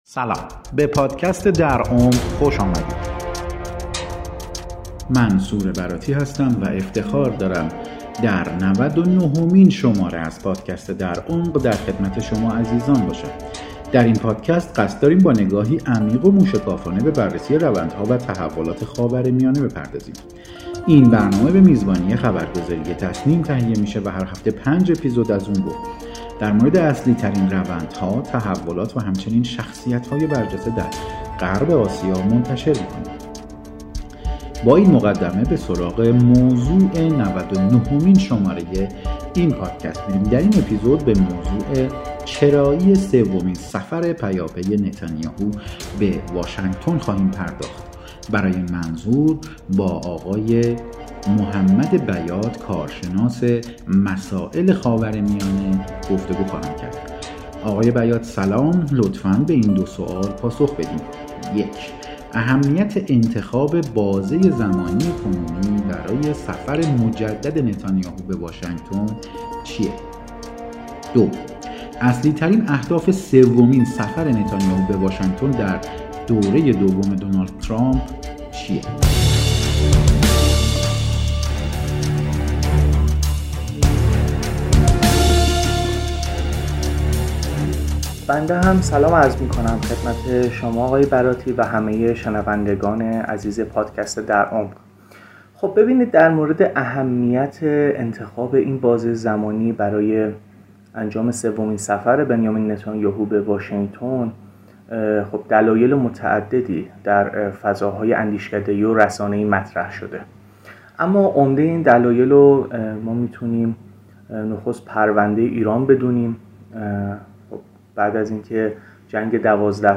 کارشناس مسائل خاورمیانه است.